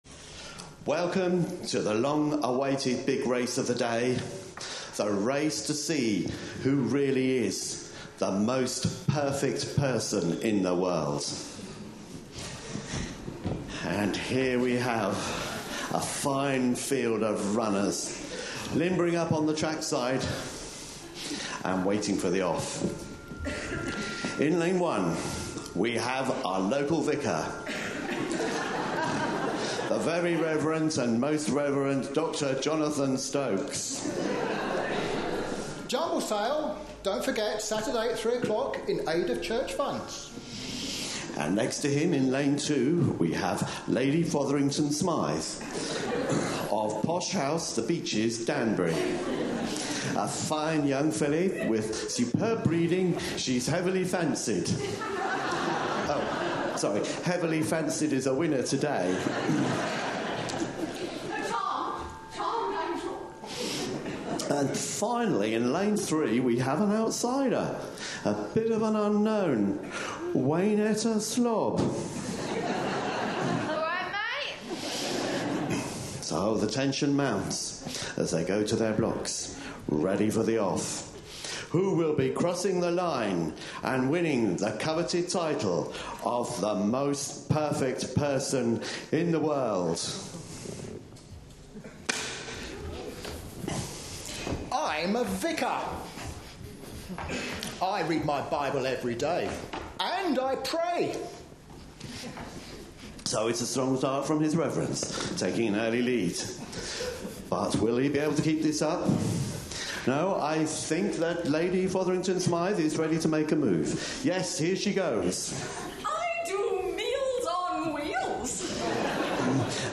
A sermon preached on 16th November, 2014.
Romans 3:21-24 Listen online Details This family service recording includes a race to find The Most Perfect Person In The World, followed by a talk based on Romans 3:21-24 and 7:18-19, with reference to a DiY repair attempt on a kitchen drawer.